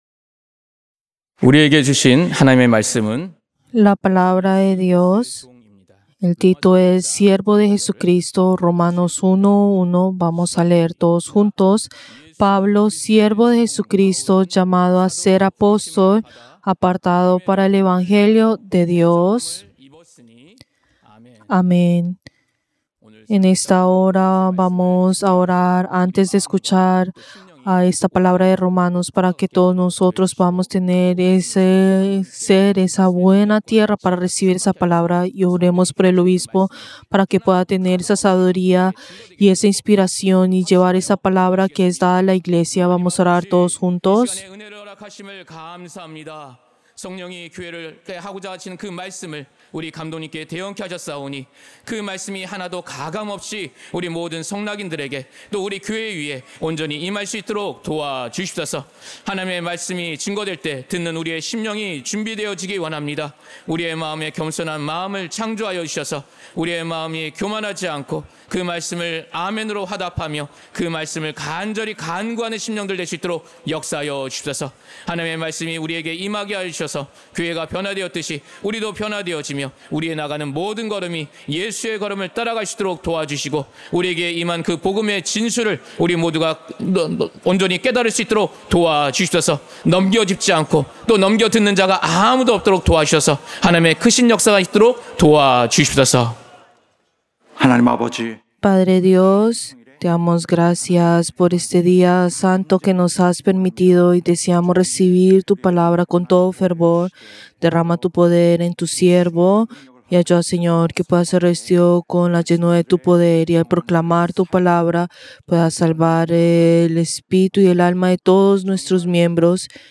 Servicio del Día del Señor del 31 de agosto del 2025